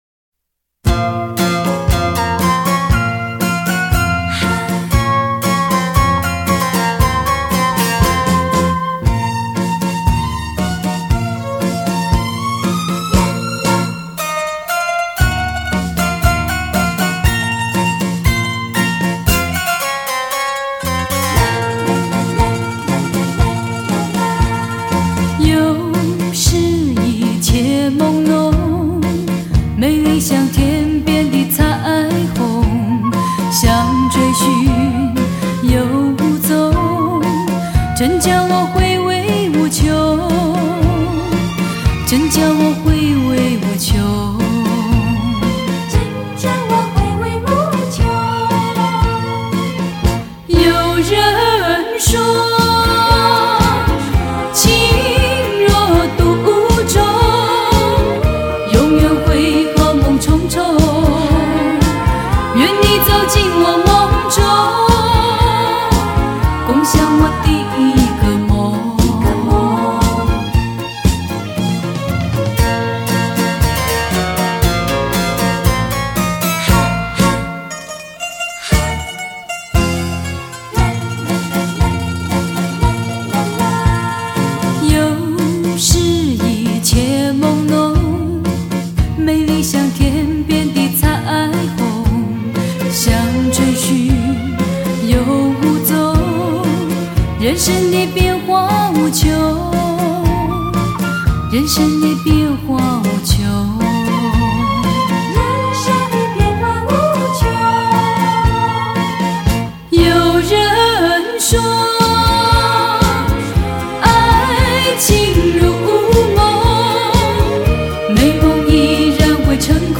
原录音、原歌声、原母带制作